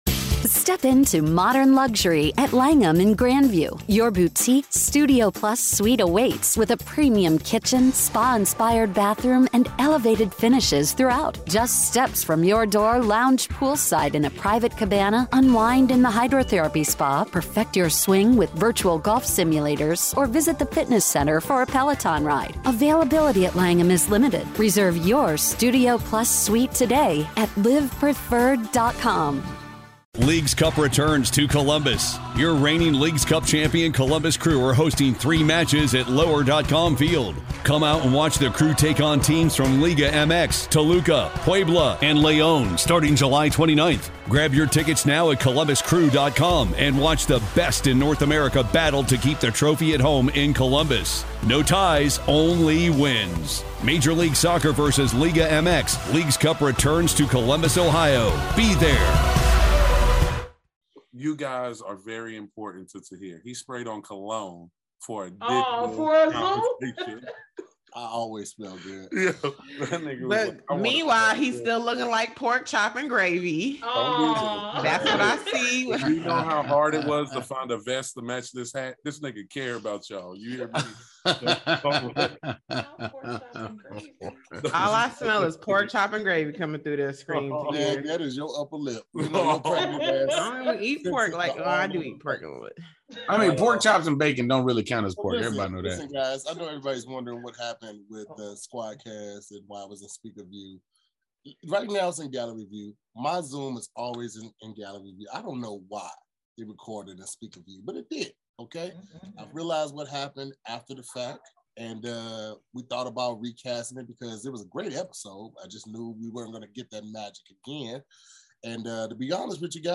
Each week, the SquADD will debate topics and vote at the end to see what wins.